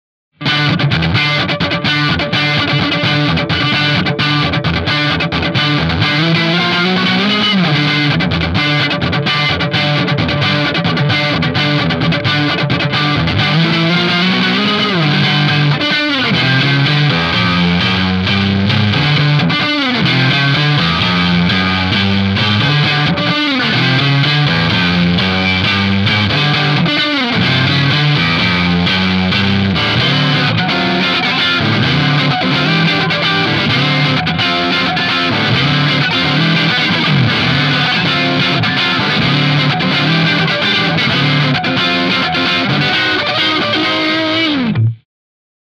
Gespielt habe ich je nach Sample eine Les Paul Style Gitarre, eine Strat oder eine Powerstrat. Als Box kam eine Marshall 4x12 mit Greenbacks zum Einsatz und abgenommen wurde mit einem SM57 direkt in den PC.
Funk
funk_06.mp3